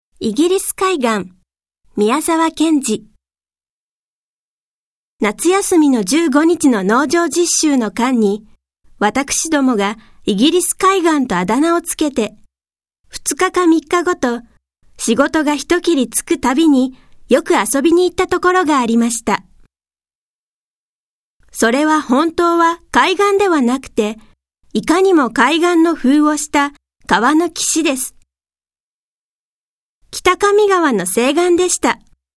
• 弊社録音スタジオ
朗読ＣＤ　朗読街道146「イギリス海岸・マグノリアの木・革トランク」
朗読街道は作品の価値を損なうことなくノーカットで朗読しています。